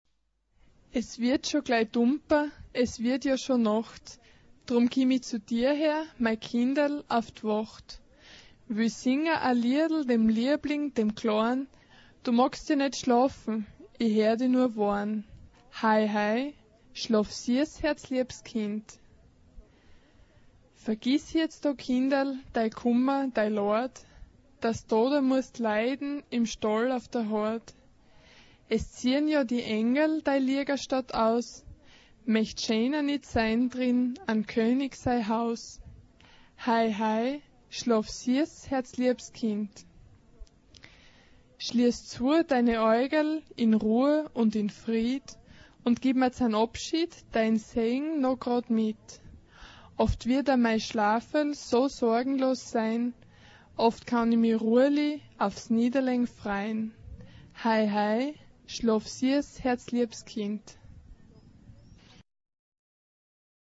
Weihnachtslied
Charakter des Stückes: hell
SA (2-stimmiger Kinderchor )
Klavier (1 Instrumentalstimme(n))
Tonart(en): A-Dur